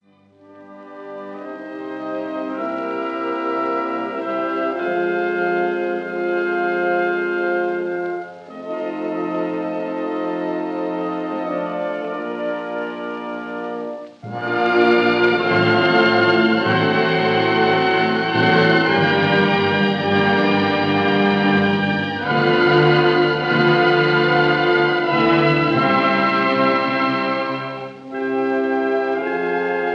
full orchestra